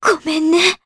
Juno-Vox_Dead_jp.wav